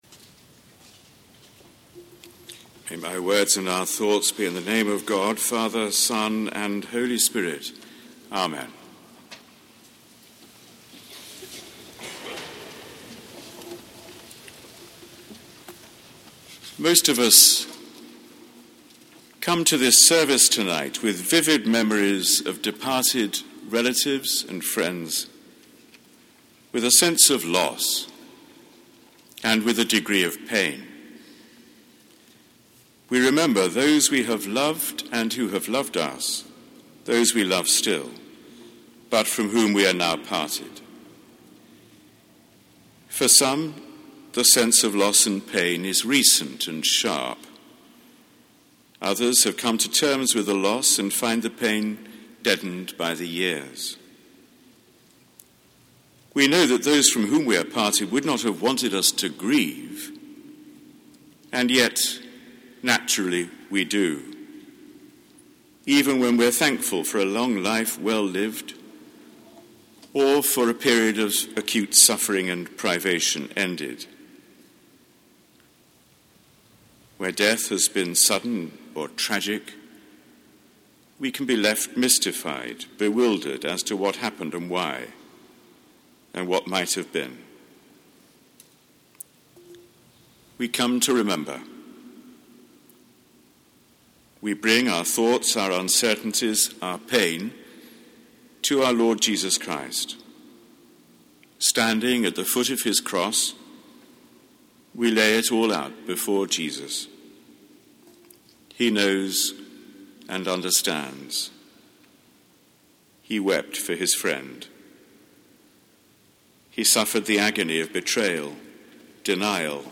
Sermon given at Sung Eucharist on All Souls' Day: Tuesday 2 November 2010
Sermon given on All Souls' Day 2010 by The Very Reverend Dr John Hall, Dean of Westminster